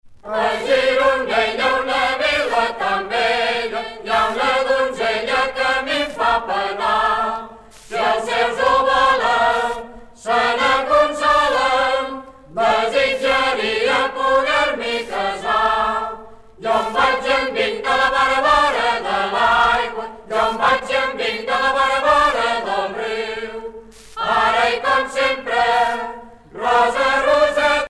Usage d'après l'analyste gestuel : danse
Catégorie Pièce musicale éditée